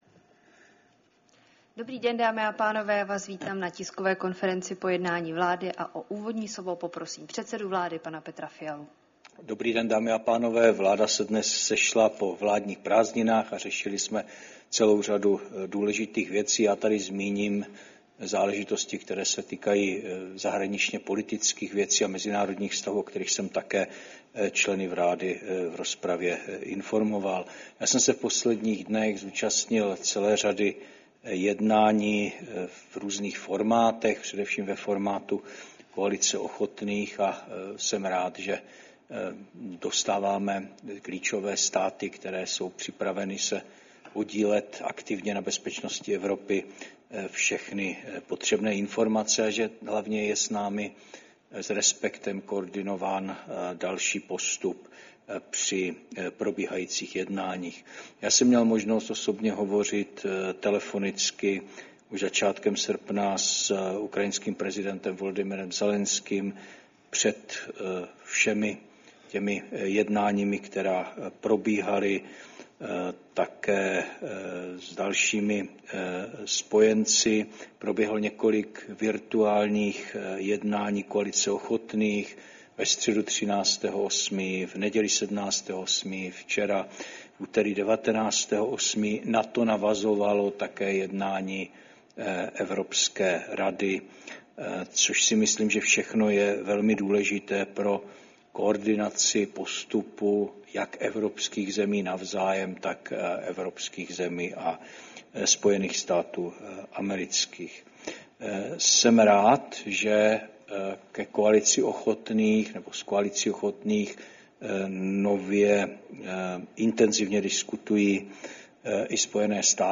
Tisková konference po jednání vlády, 20. srpna 2025